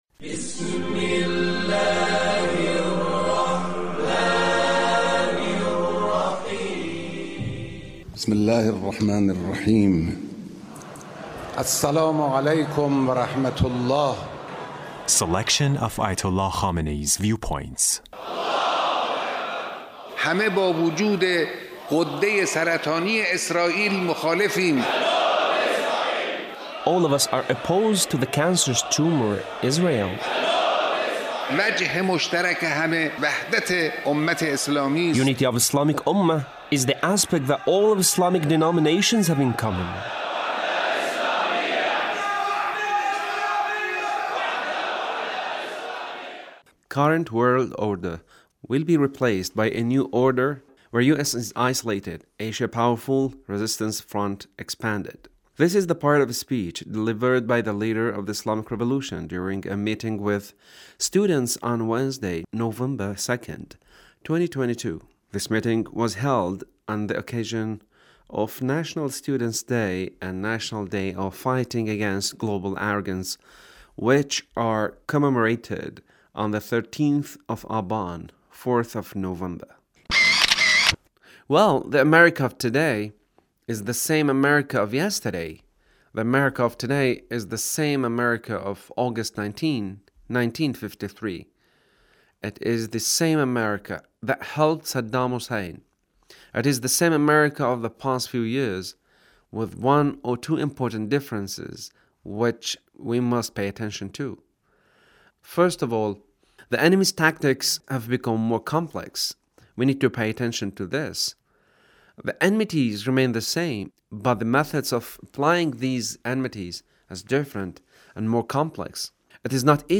Leader's Speech on 13 th of Aban